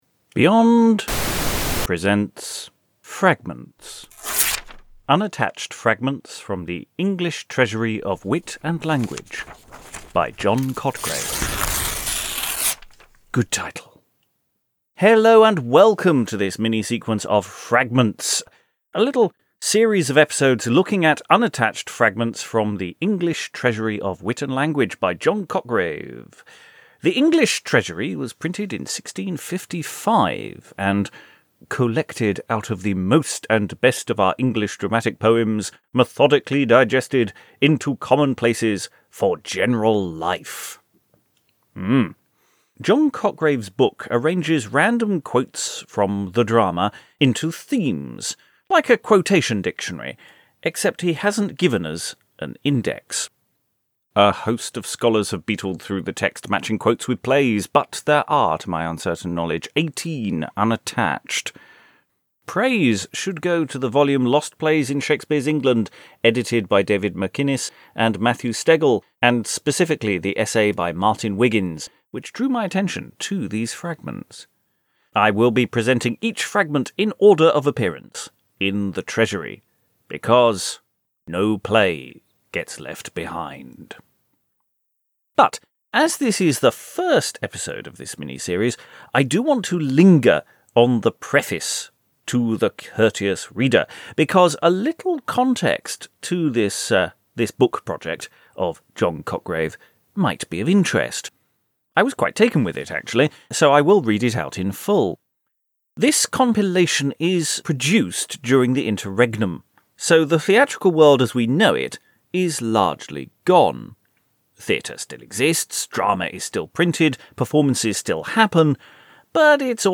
Beyond is sponsored by The Malone Society: The permanent utility of original texts It's the first of an epic (yet dinky) 18 part series looking at some fragments found in The English Treasury of Wit and Language by John Cotgrave. This is a fairly chunky opening gambit, with the preface to the book, before our usual drive by at a short bit of text.